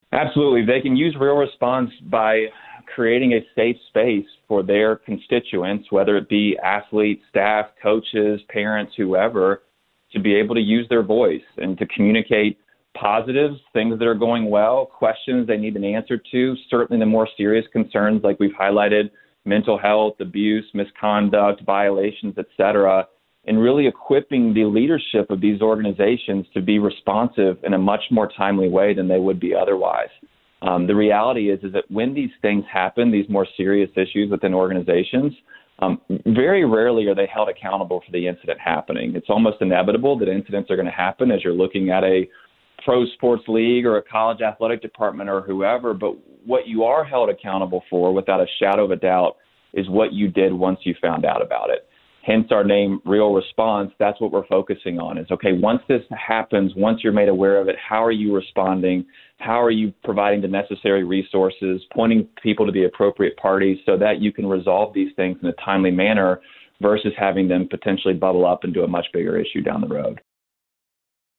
ESPNU Radio Interview